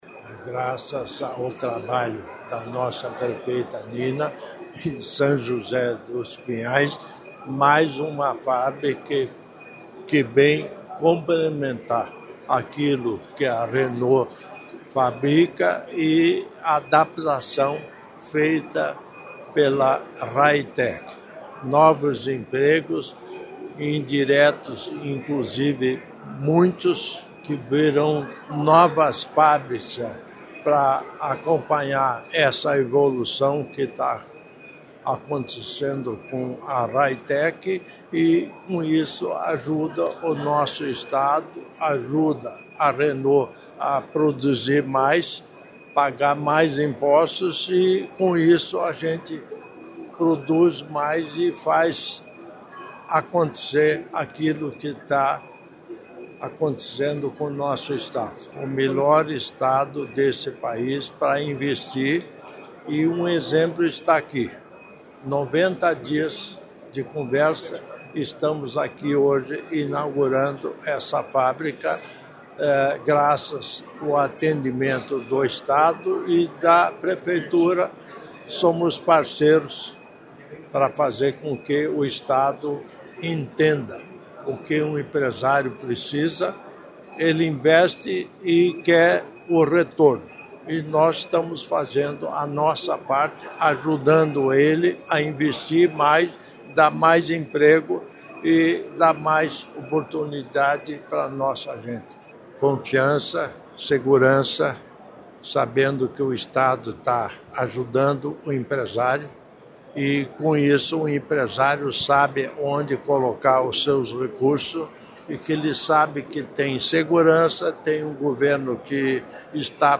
Sonora do governador em exercício Darci Piana sobre a inauguração da fábrica da Raytec, na RMC